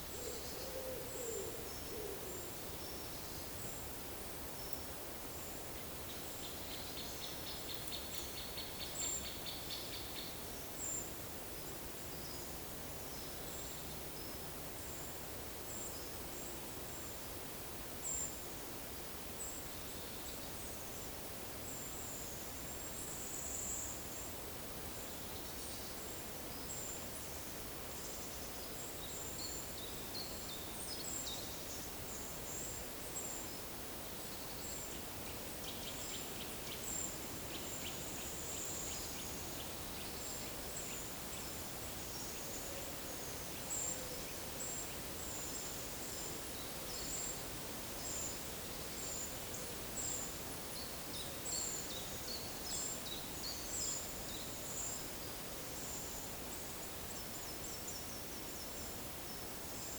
Monitor PAM - Renecofor
Regulus regulus
Certhia familiaris
Certhia brachydactyla
Phylloscopus collybita
Sitta europaea
Leiopicus medius
Columba palumbus
Columba oenas